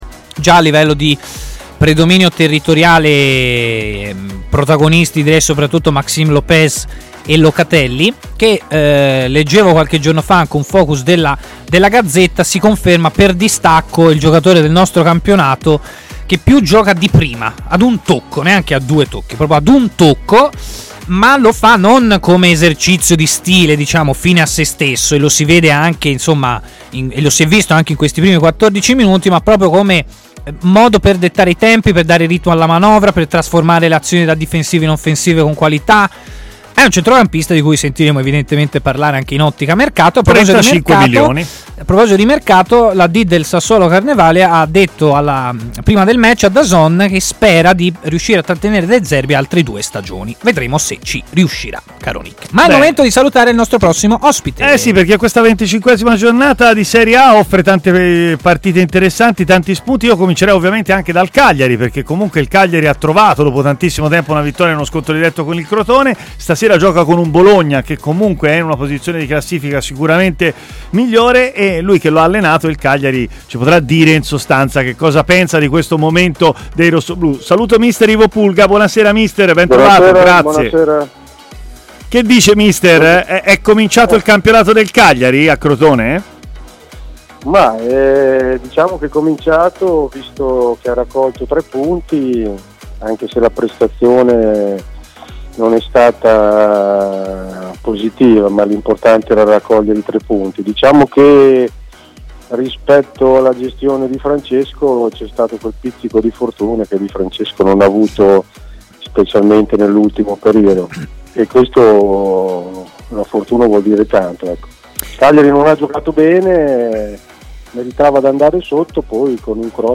intervenuto in diretta a TMW Radio, nel corso della trasmissione Stadio Aperto